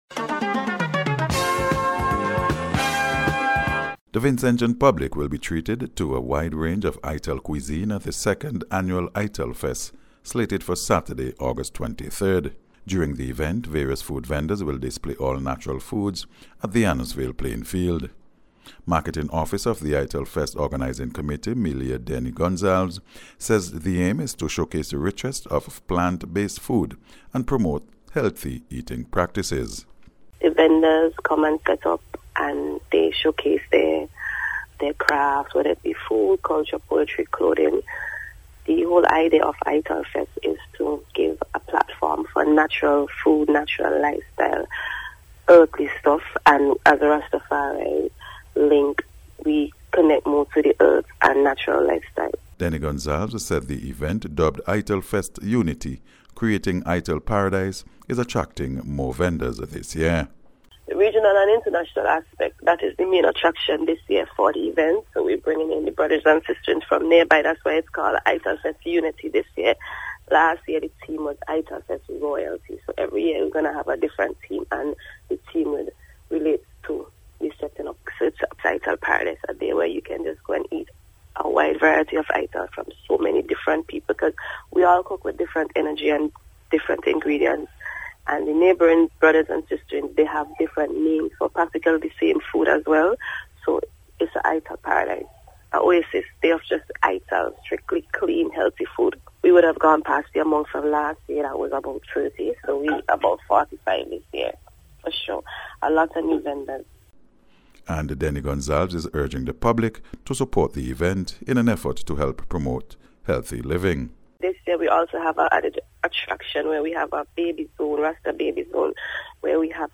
NBC’s Special Report- Monday 11th August,2025